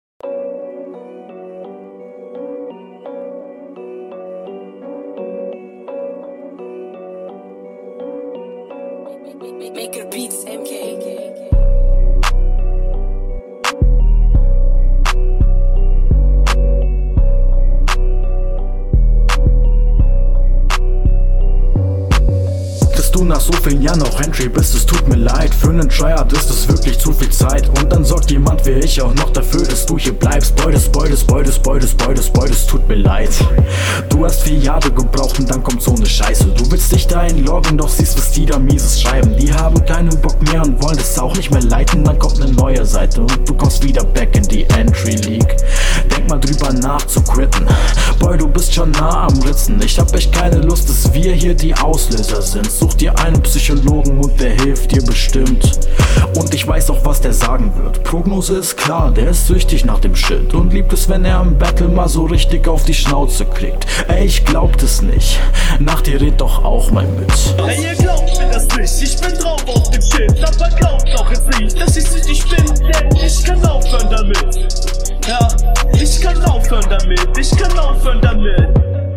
Sound auf dem Beat ziemlich nice.
Trap Beat mit fedder 808, schmeckt, Rap ist fresh aber klingt nicht ganz so routiniert/rund …